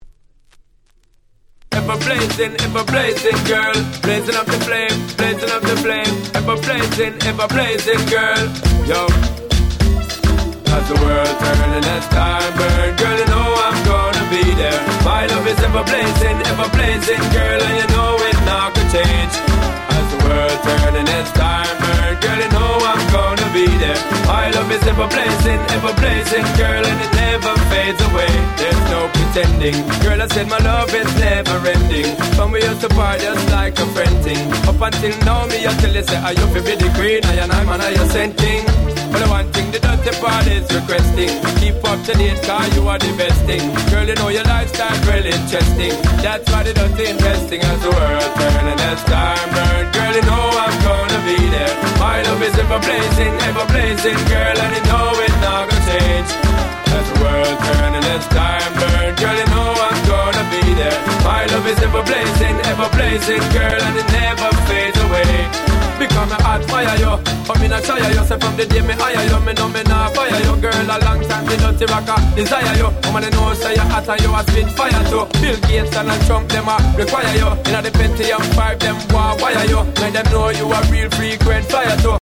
02' Super Hit Dancehall Riddim !!